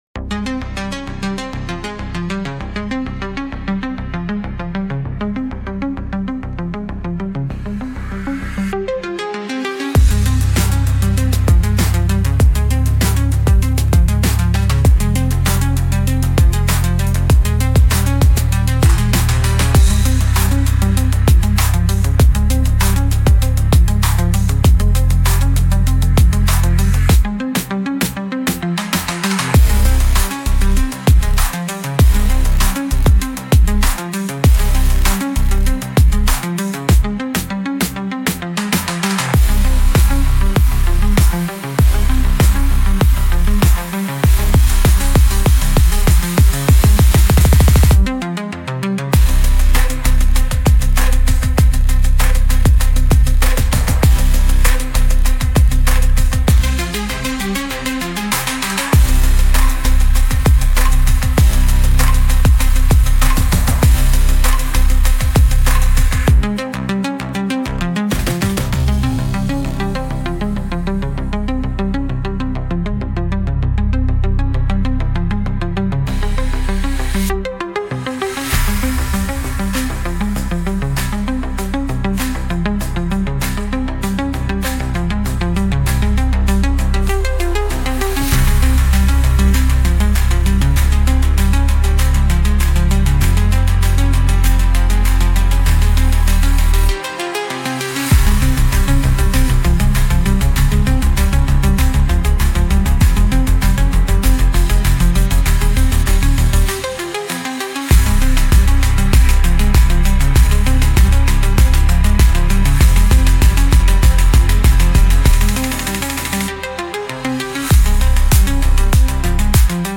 Instrumental - Truth Under Armor